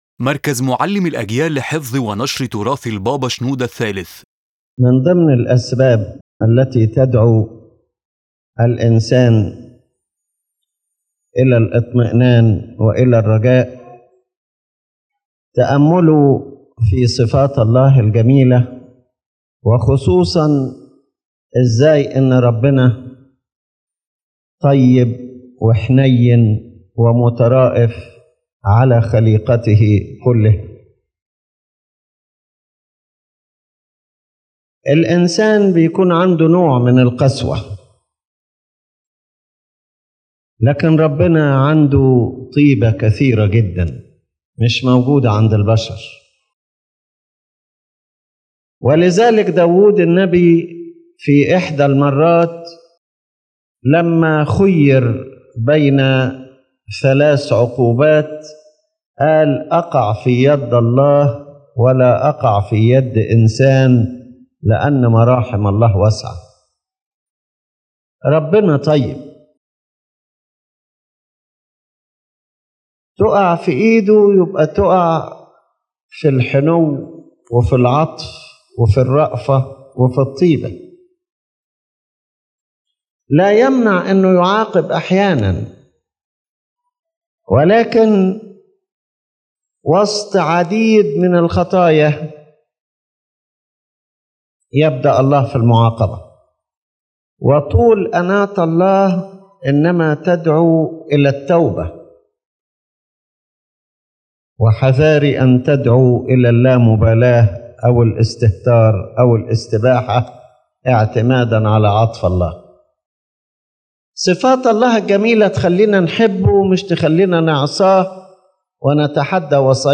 His Holiness Pope Shenouda III reflects on God’s kindness and compassion toward His creation, and how this kindness invites humans to reassurance and hope and urges us to love God and imitate His attributes in our mercy toward others and all creatures.